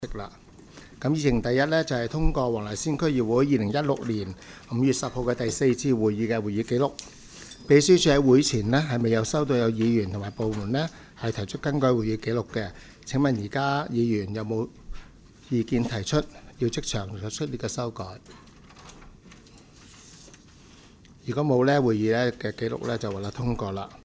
区议会大会的录音记录
黄大仙区议会第五次会议